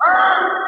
Metro Vox 9.wav